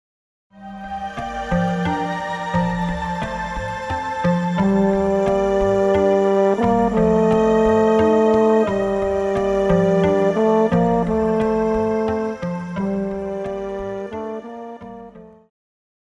Pop
French Horn
Band
Instrumental
World Music,Electronic Music
Only backing